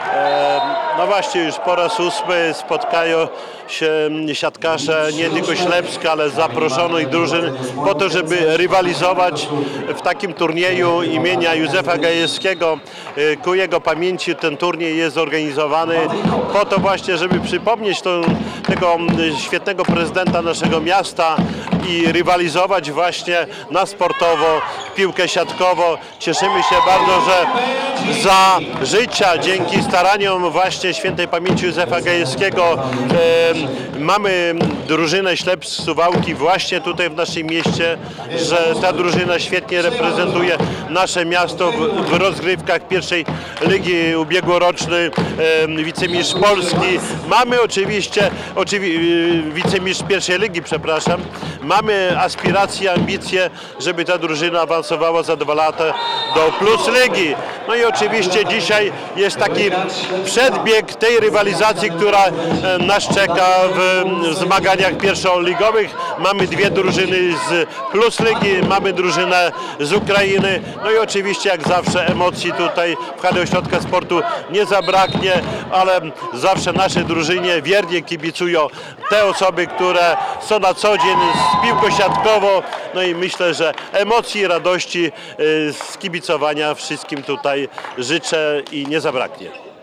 -Drużyna ma mnóstwo kibiców i cieszy się wysokimi osiągnięciami- powiedział Radiu 5 Czesław Renkiewicz, prezydent miasta Suwałki